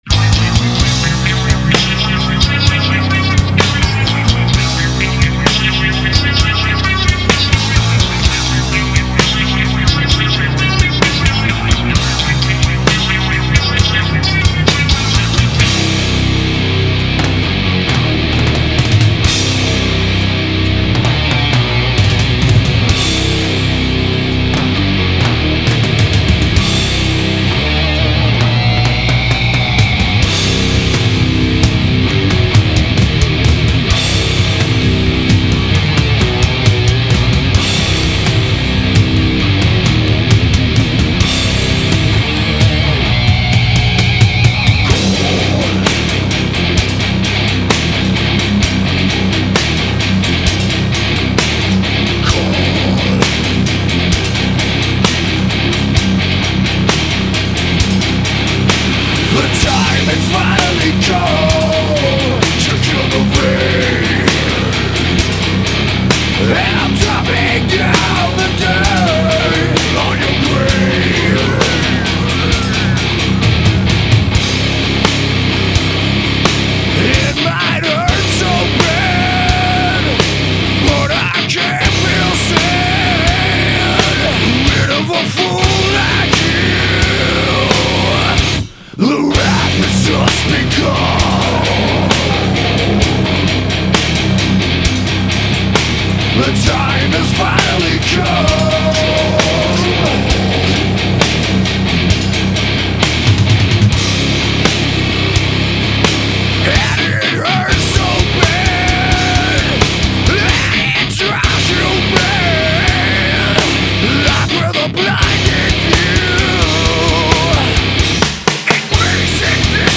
گروو متال
ریف های گیتار های متفاوت و قشنگی داره